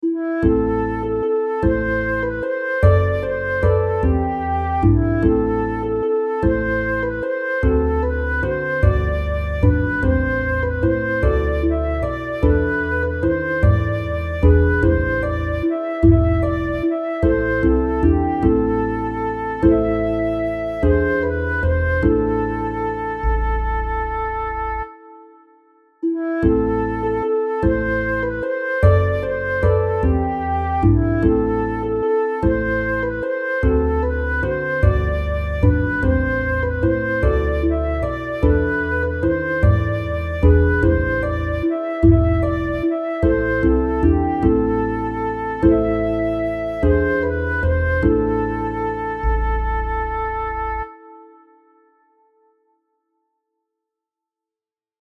It fits well, assuming that some of the syllables are sung over multiple notes.
You can also download the music files (Zip file, 1.3 MB) I used to create the MIDI files that I used to create the melody, using the piano script from 42 Astoundingly Useful Scripts and Automations for the Macintosh.